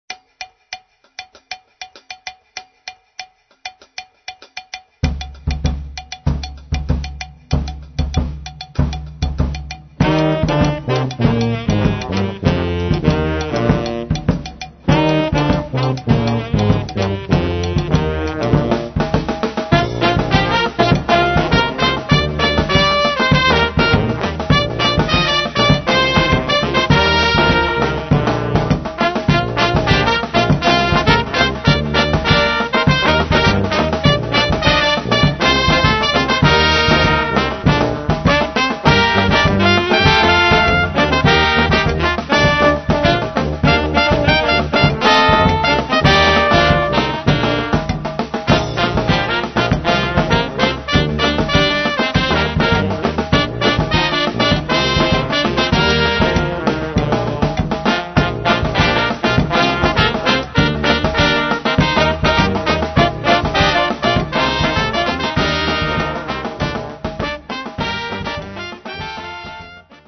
dans un style parfois drôle, parfois décalé, toujours festif